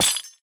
Minecraft Version Minecraft Version snapshot Latest Release | Latest Snapshot snapshot / assets / minecraft / sounds / block / chain / break2.ogg Compare With Compare With Latest Release | Latest Snapshot